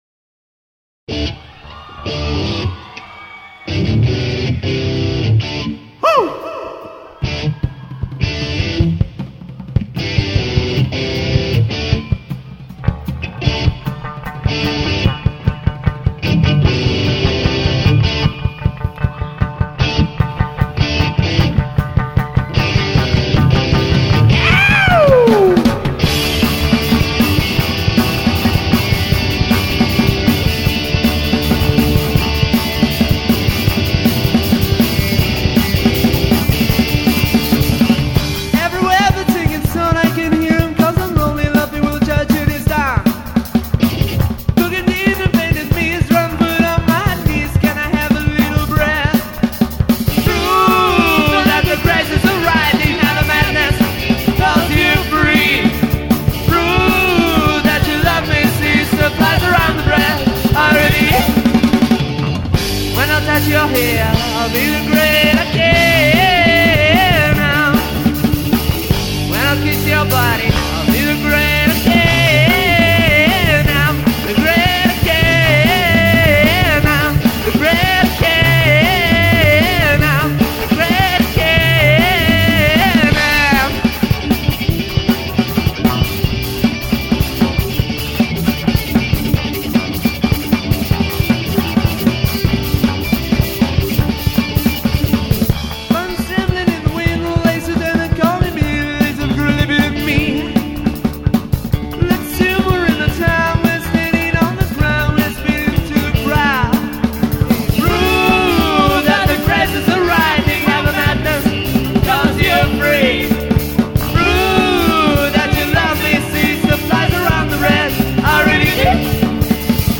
LIVE BONUS: